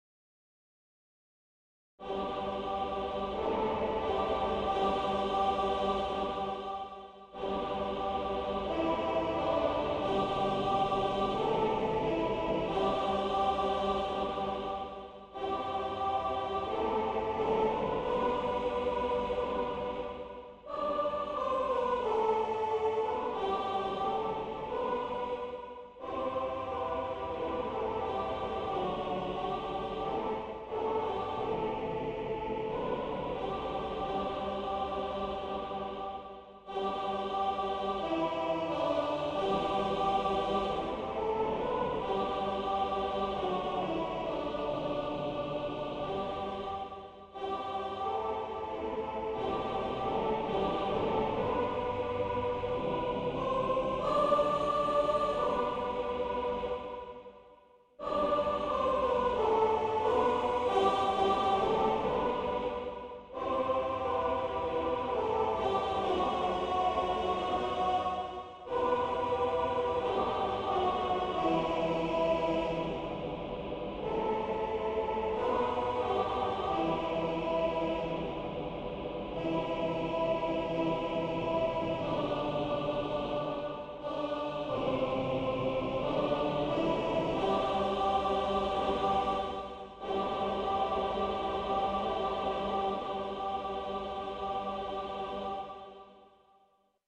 Filage audio synthétique (sans paroles)